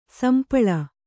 ♪ sampaḷa